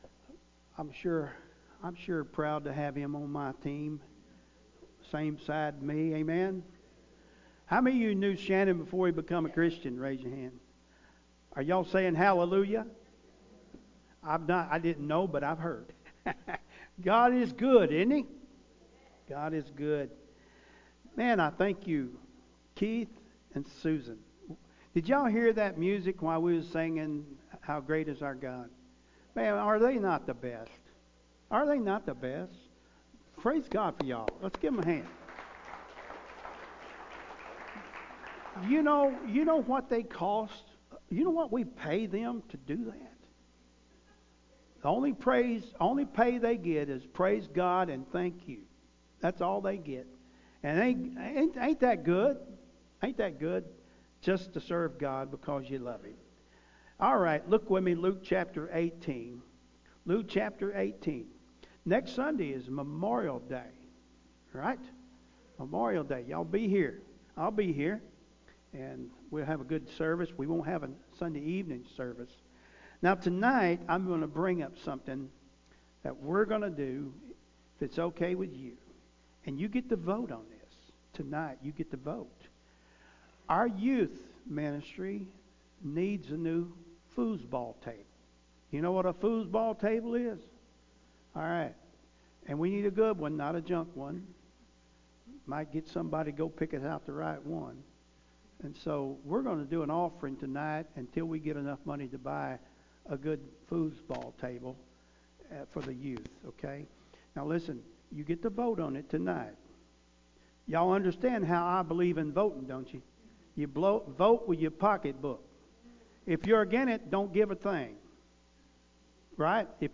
Sunday Morning Service